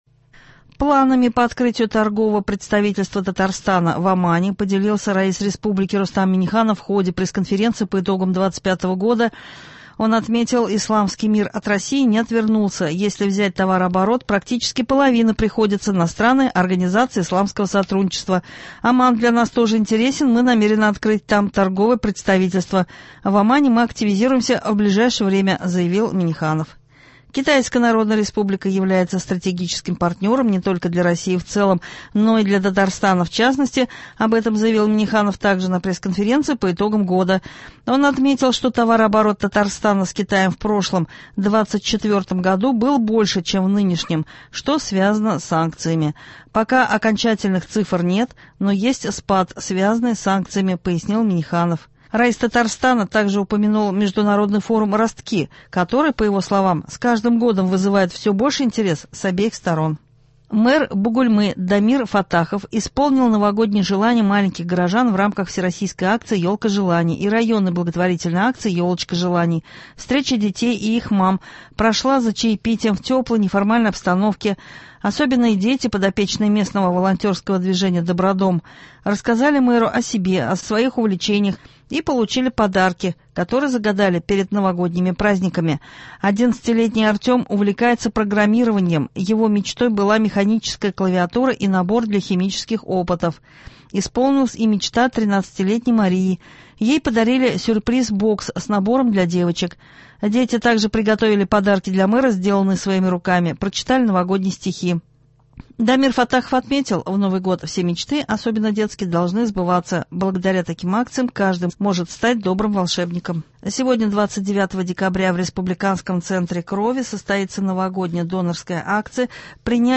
Новости (29.12.25)
Утренний выпуск